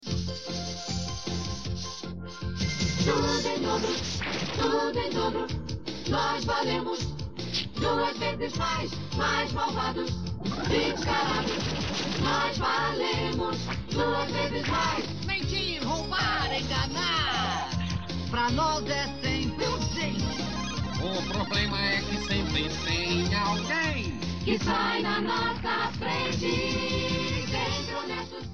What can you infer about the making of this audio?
The song as translated in the Brazilian Portuguese dub